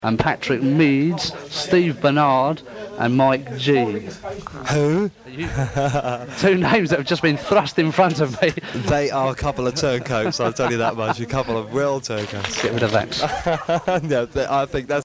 The broadcast quality of Solar Radio that day was so amateurish (hiss up in a brewery comes to mind) to say the least as studio microphones did not work half the time and the OB was a shambles.